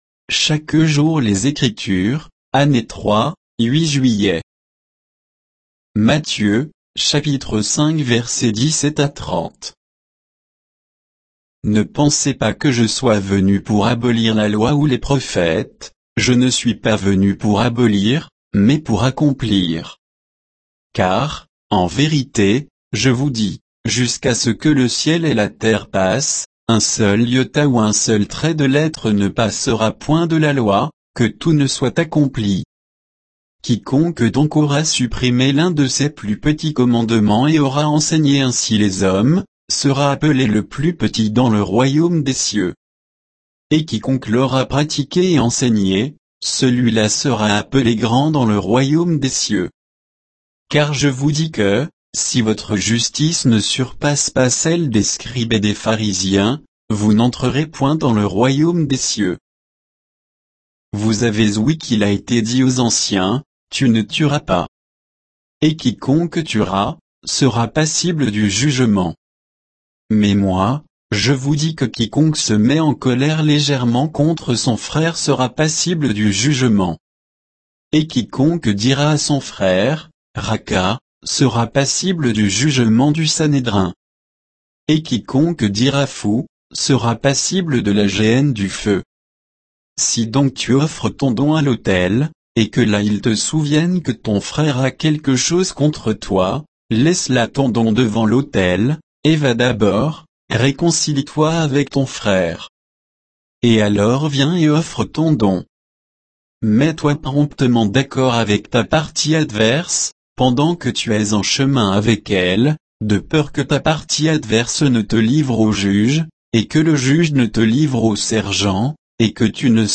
Méditation quoditienne de Chaque jour les Écritures sur Matthieu 5, 17 à 30